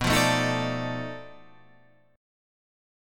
B7#9b5 chord